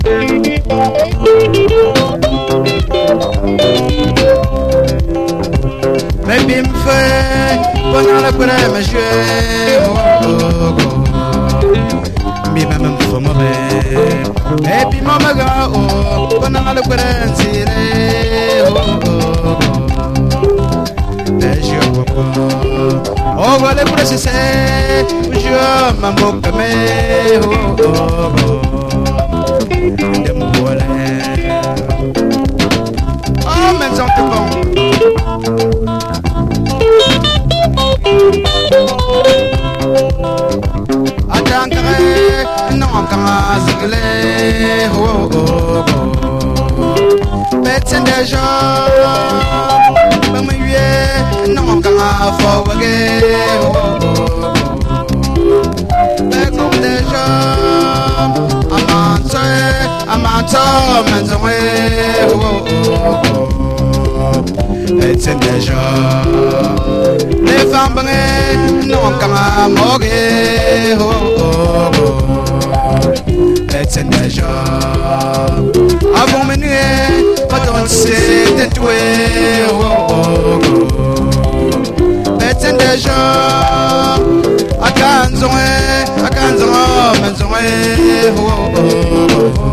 甘いメロディー・ラインが魅力の90'S UKラヴァーズ！
ラヴァーズ的なシルキーな感触でありつつ、しかりルーツ・レゲエの骨太さも感じさせます！